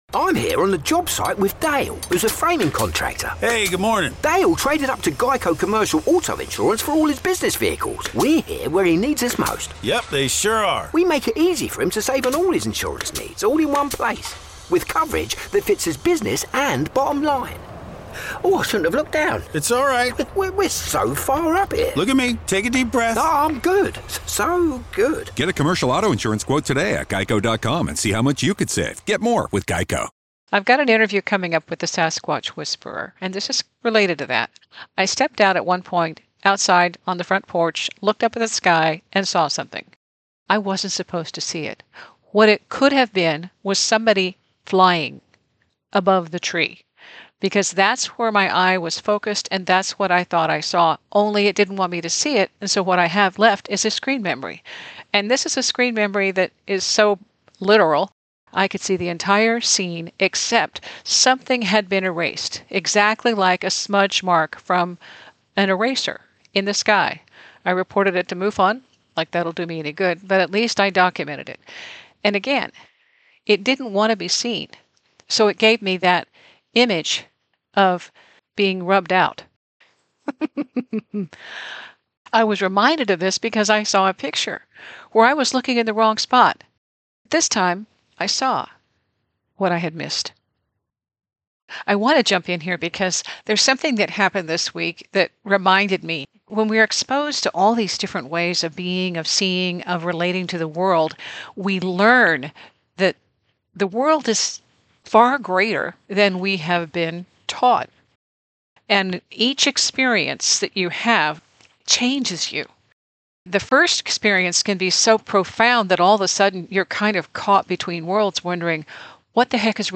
And here’s the bonus in this episode: an EVP.